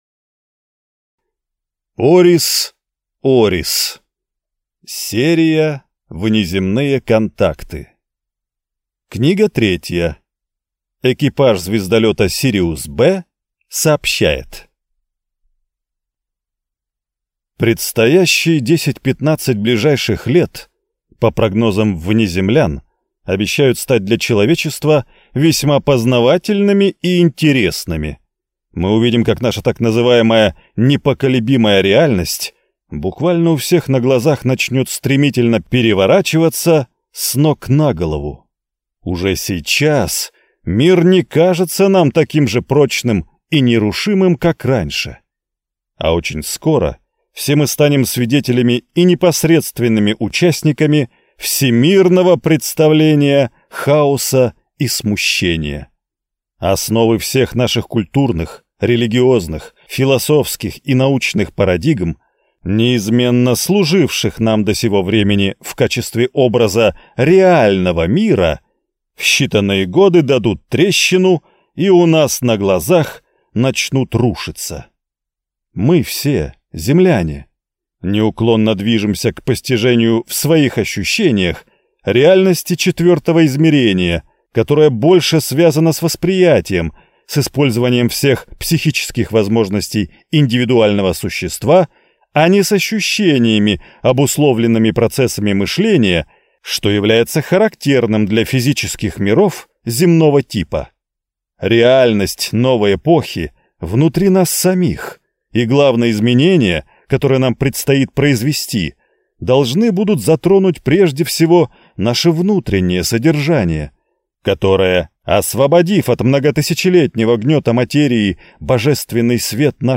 Аудиокнига Экипаж звездолёта «Сириус-Б» сообщает | Библиотека аудиокниг